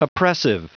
Prononciation du mot oppressive en anglais (fichier audio)
Prononciation du mot : oppressive